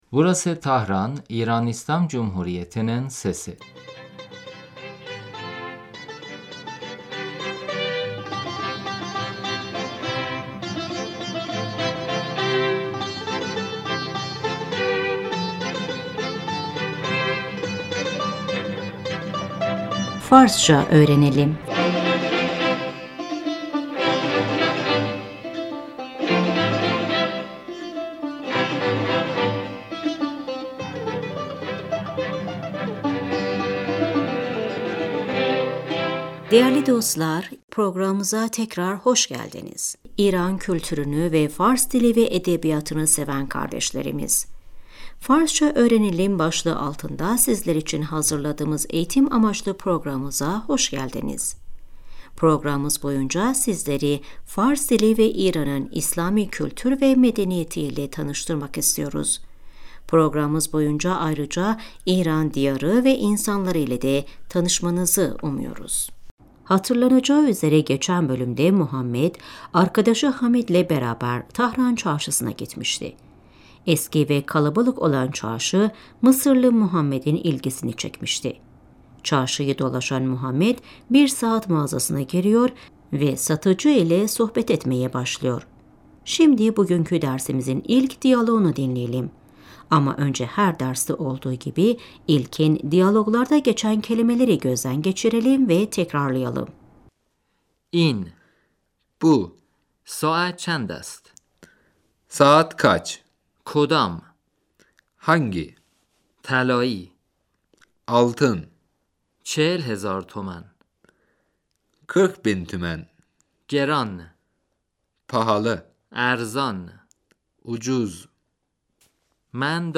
Şimdi yeni diyaloğu dinleyin ve tekrarlayın. صدای بازار - همهمه مردم Çarşı ve kalabalığın sesi محمد - ببخشید آقا ، این ساعت چند است ؟